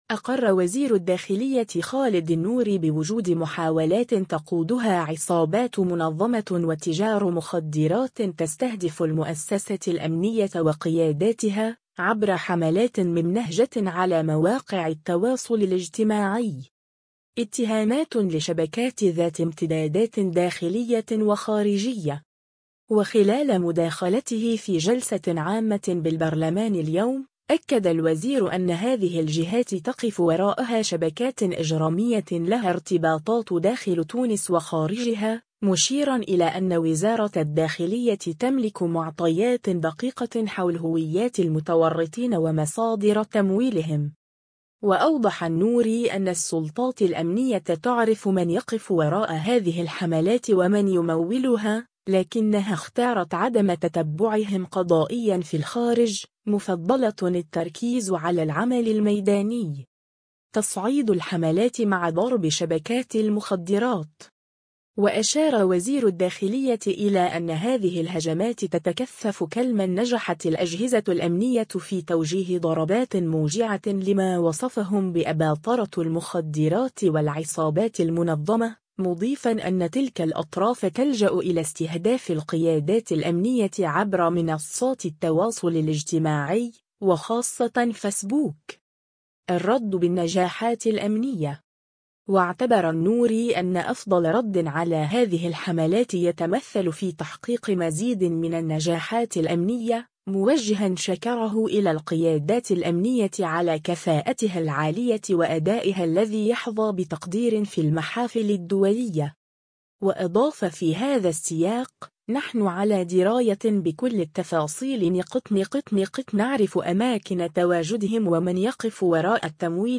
وخلال مداخلته في جلسة عامة بالبرلمان اليوم، أكد الوزير أن هذه الجهات تقف وراءها شبكات إجرامية لها ارتباطات داخل تونس وخارجها، مشيرًا إلى أن وزارة الداخلية تملك معطيات دقيقة حول هويات المتورطين ومصادر تمويلهم.